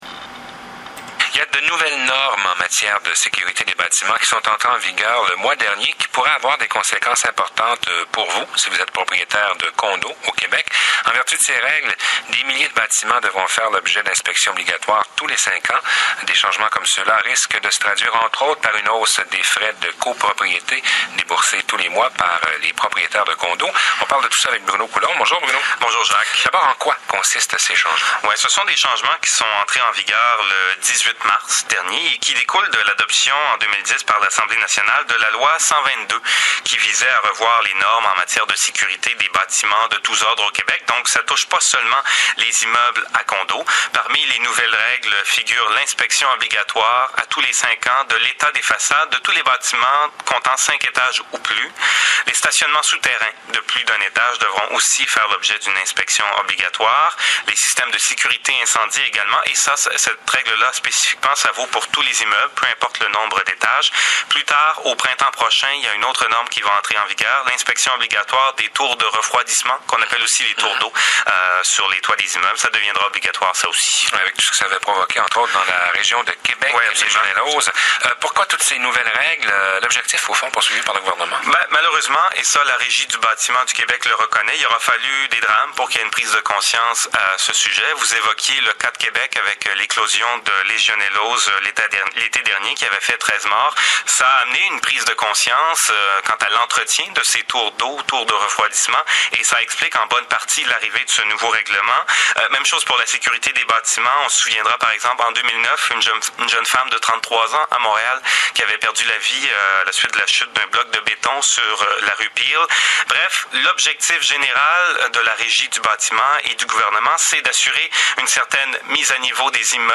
Reportage sur les nouvelles règles en matière de sécurité des bâtiments (Première chaîne de Radio-Canada - Désautels) 5 avril 2013